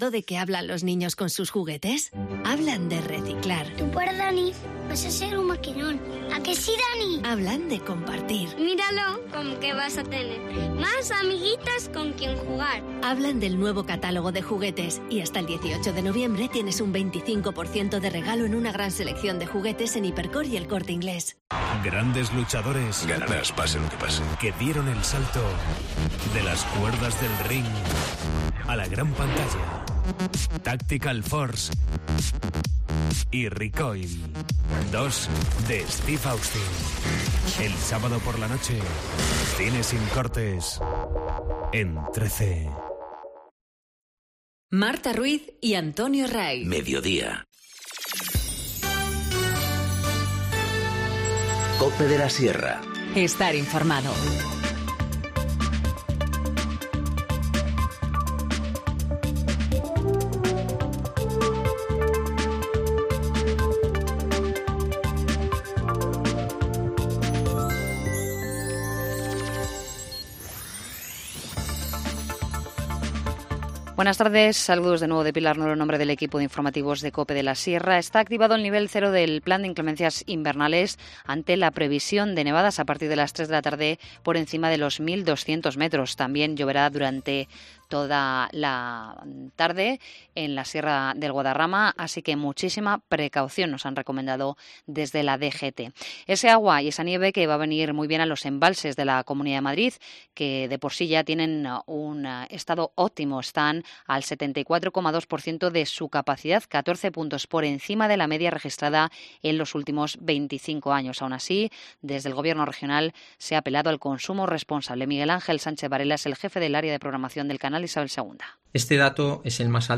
INFORMATIVO MEDIODÍA 5 NOV- 14:50H